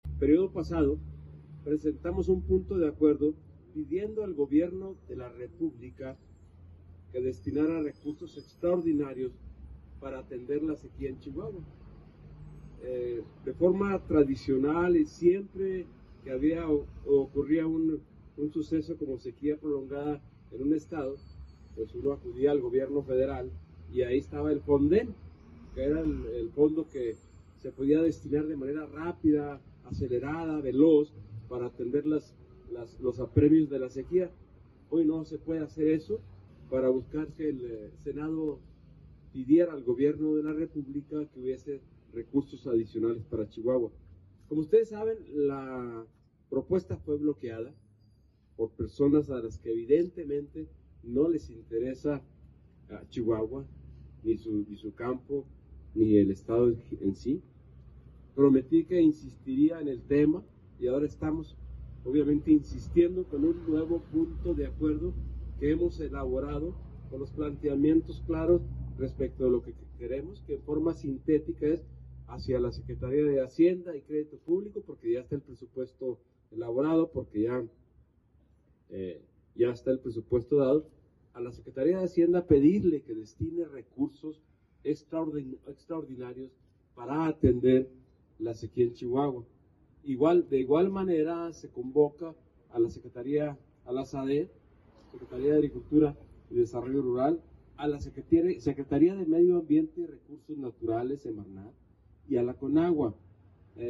El senador Mario Vázquez afuera de las instalaciones de la Secretaría de Agricultura y Desarrollo Rural (SADER) sostuvo su acusación sobre la exclusión del estado en el Plan Hídrico Nacional y adelantó que presentará una iniciativa de ley en la Cámara de Diputados para que la Secretaría de Hacienda y Crédito Público asigne recursos adicionales a Chihuahua para enfrentar la sequía.